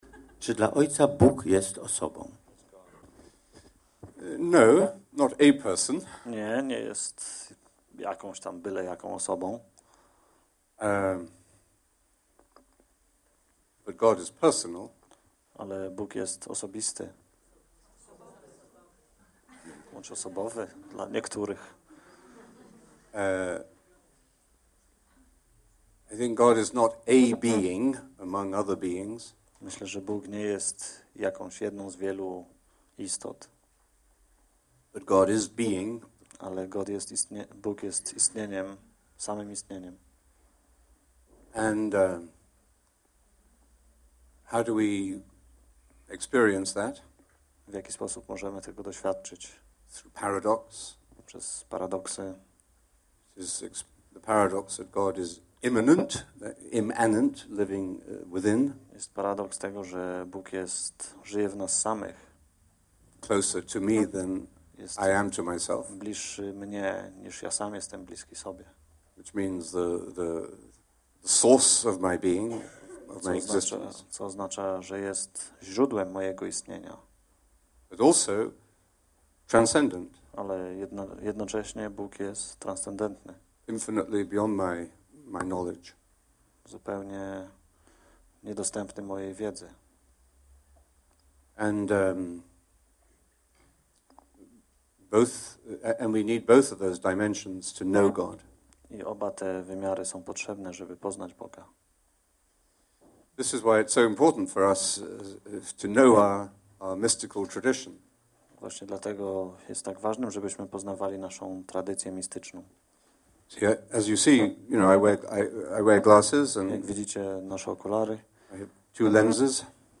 Pliki mp3 z nagrań konferencji:
Dyskusja: Cz.14Cz.15Cz.16